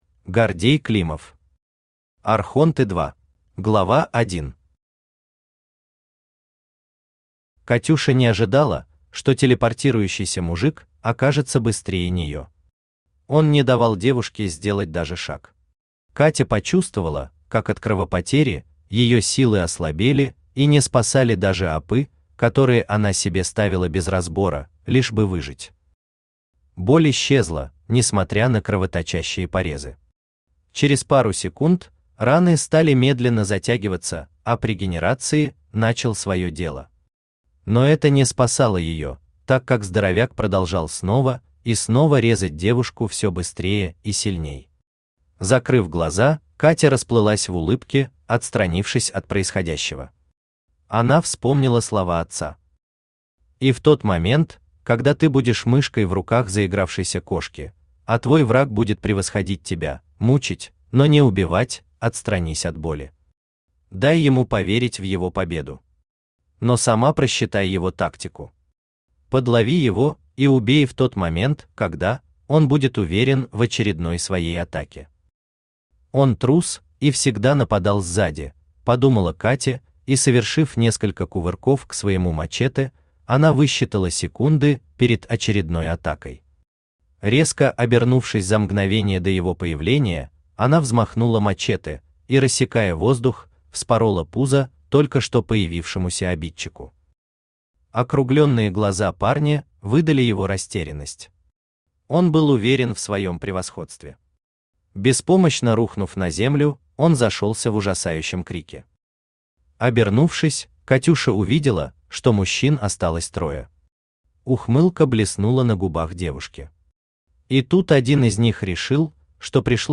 Аудиокнига Архонты 2 | Библиотека аудиокниг
Aудиокнига Архонты 2 Автор Гордей Климов Читает аудиокнигу Авточтец ЛитРес.